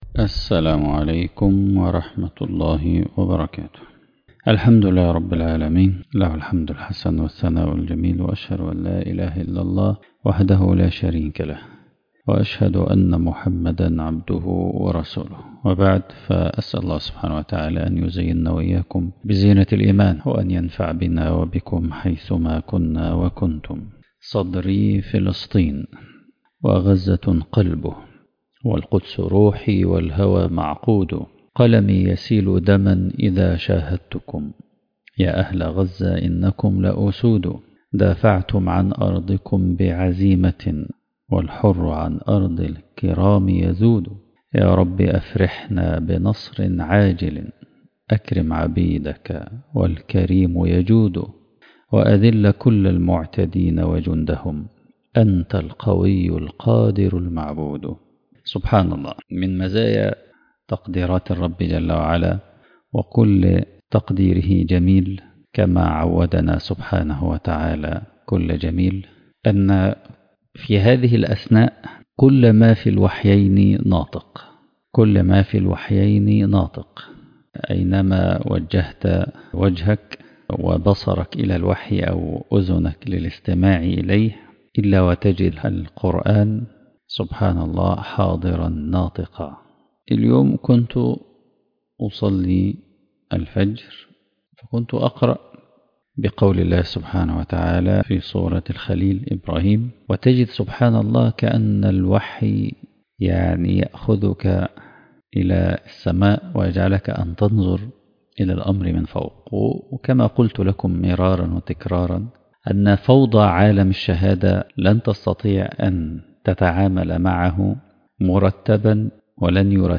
اللهم منزل الكتاب | المحاضرة الثالثة